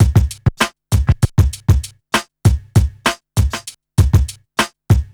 1TI98BEAT2-R.wav